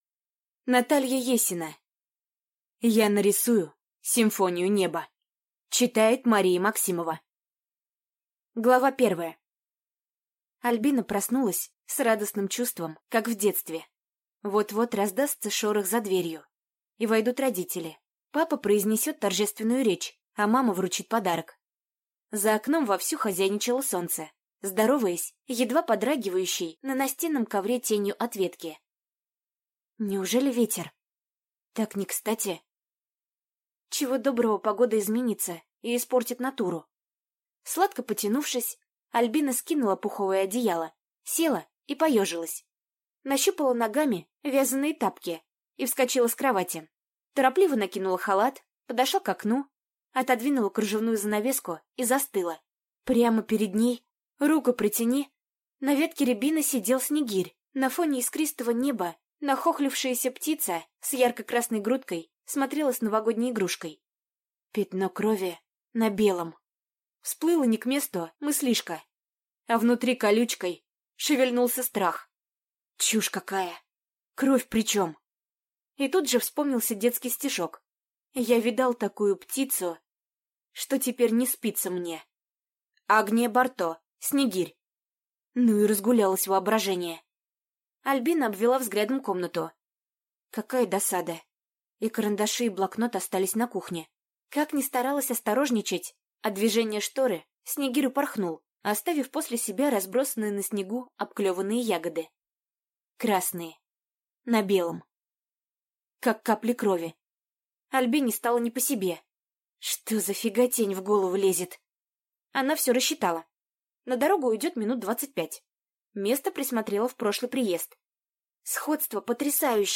Аудиокнига Я нарисую симфонию неба | Библиотека аудиокниг